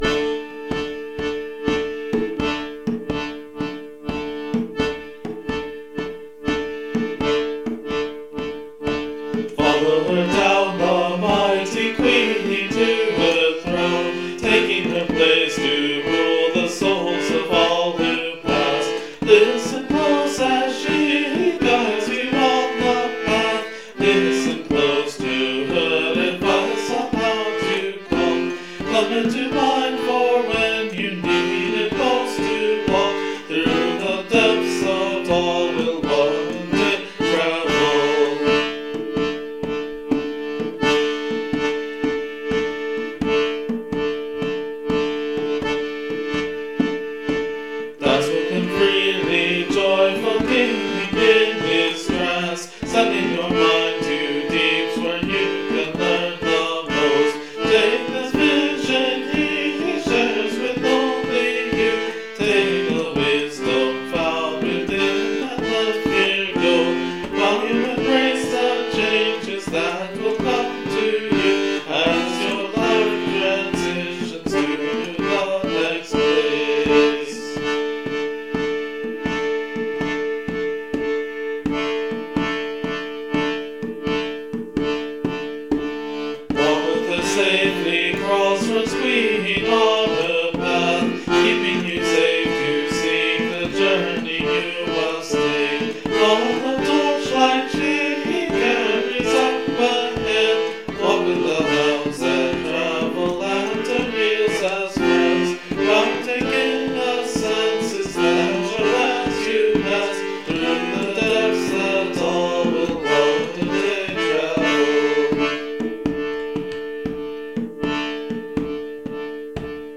This is definitely a bit of a rhythmic experiment: The pattern is a combination of long and short beats, going long-short-short-long, or 3+2+2+3.
I used the D Dorian scale, which is very similar to a D minor but has that very haunting sound of the sharp 6th note of the scale, also allowing for G major chords in an otherwise minor key. My hope is that this combination presents a reasonably otherworldly journey.